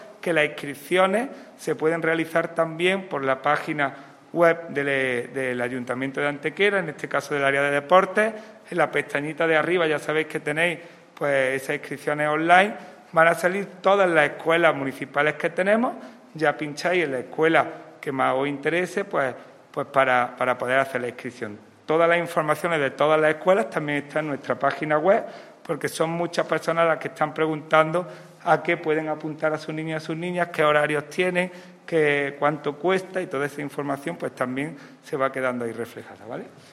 El teniente de alcalde delegado de Deportes, Juan Rosas, ha presentado en rueda de prensa la puesta en marcha de otras dos escuelas deportivas municipales de cara a la nueva temporada deportiva 2021-2022.
Cortes de voz